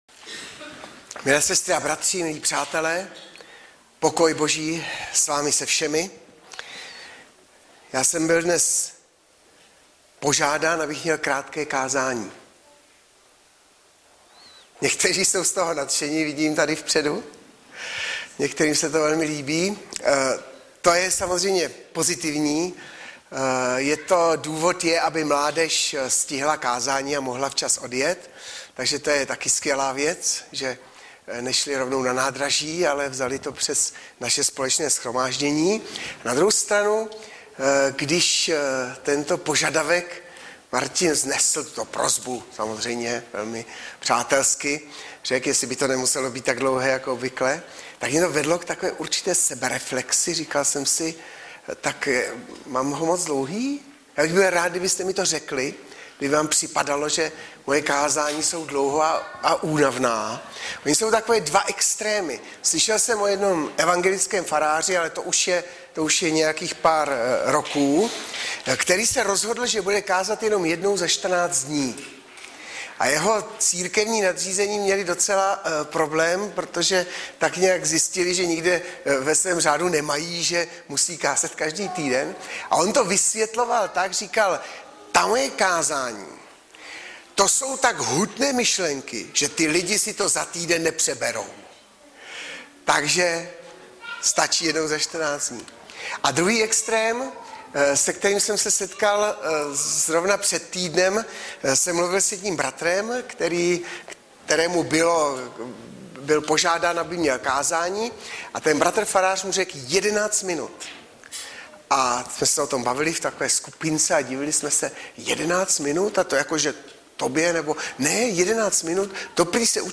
Hlavní nabídka Kázání Chvály Kalendář Knihovna Kontakt Pro přihlášené O nás Partneři Zpravodaj Přihlásit se Zavřít Jméno Heslo Pamatuj si mě  26.07.2015 - evangelizace I. KRÁTKÉ KÁZÁNÍ - 1.